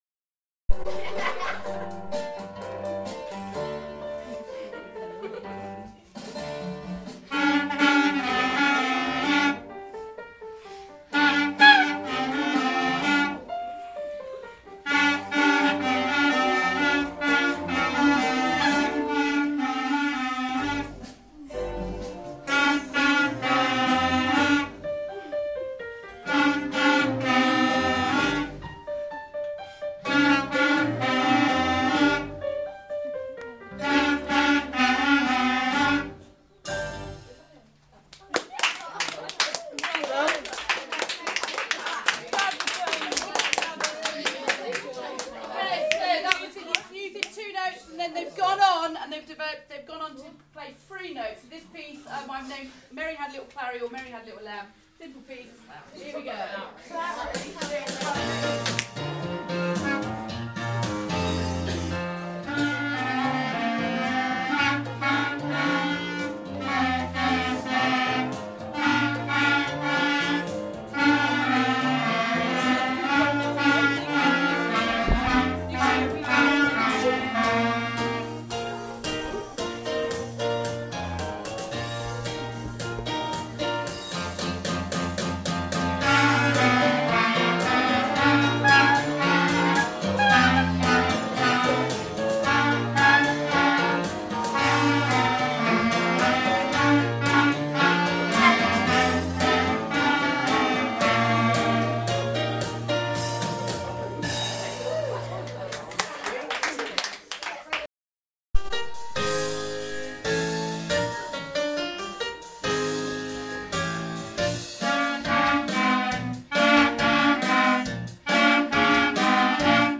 8m clarinets